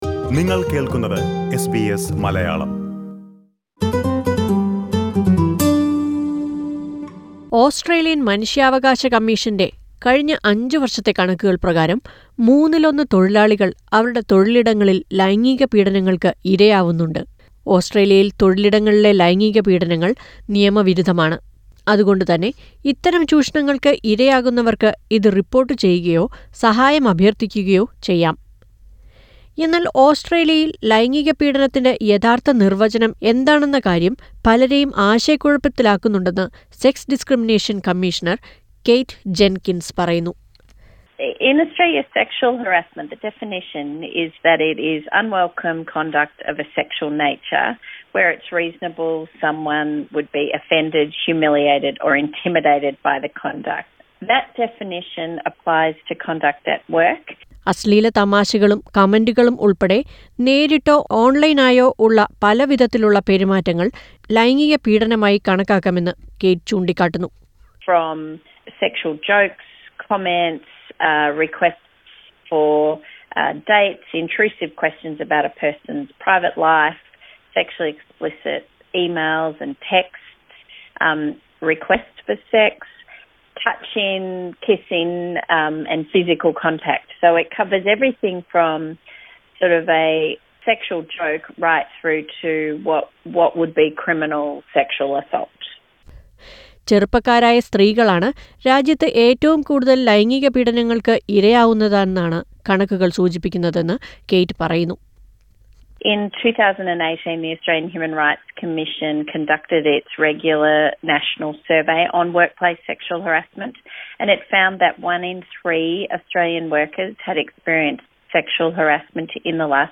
Being sexual harassed is still too common in Australia, but there are ways to ensure that your rights are respected. Listen to a report on this.